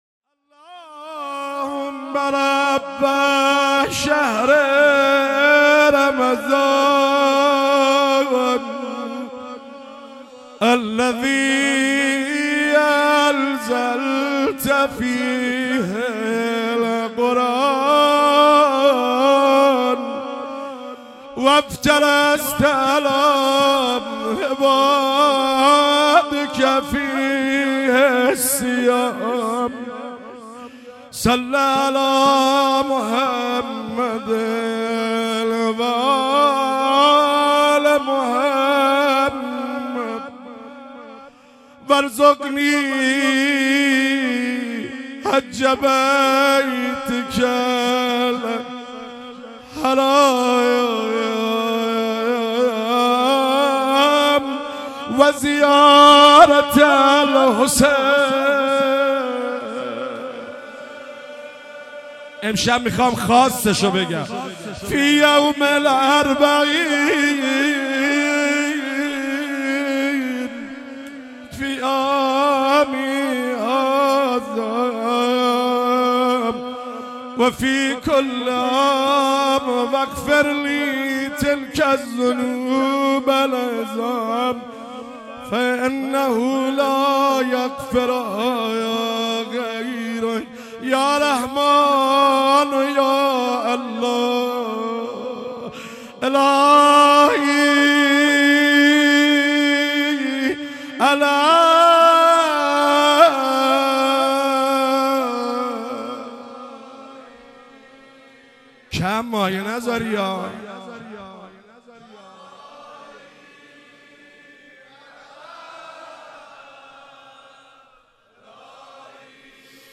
روضه - آغوش بگشا بنده ات آمد دوباره